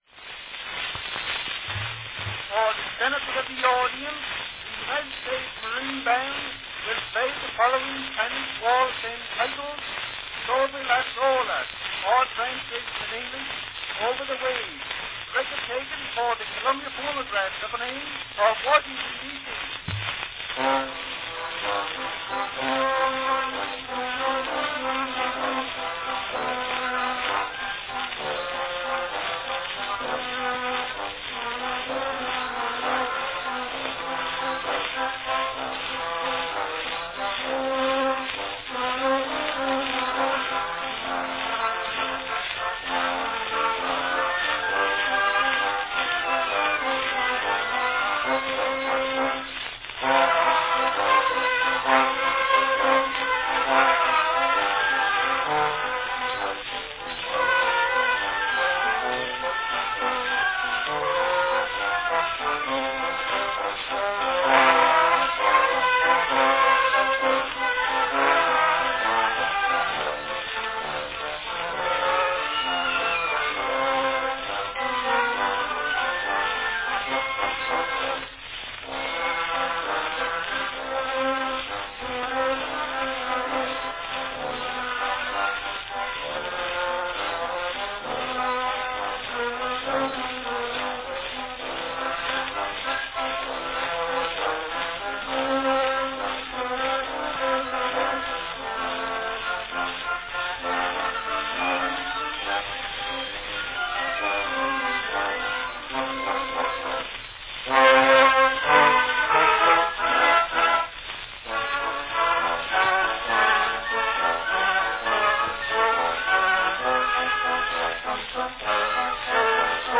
Category Spanish waltz (band)
Performed by United States Marine Band
This recording was made during the early years of the Columbia Phonograph Company, at a time when their recordings were given the extra care of an individual announcement (listen for the thunks before and after the announcement).
An example of the raised ridges on a North American Phonograph Company wax cylinder.